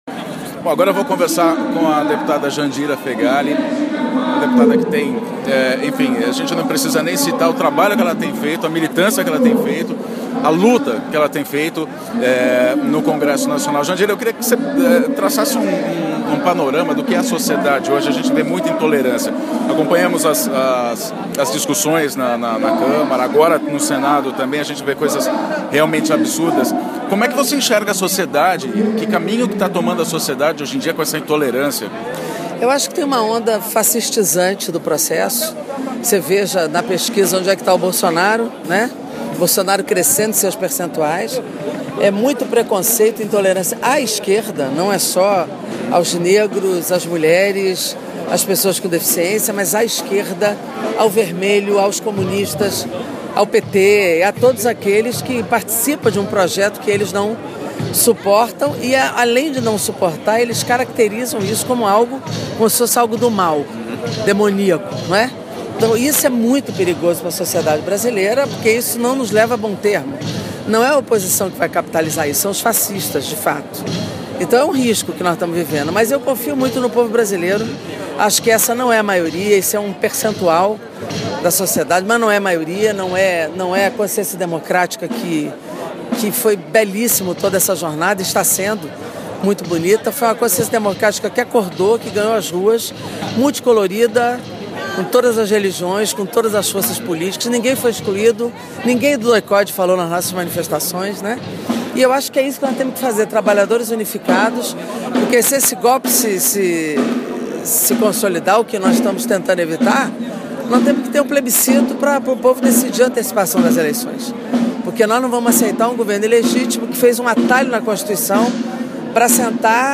Entrevista com a Deputada Jandira Feghali ao vivo do 1° de Maio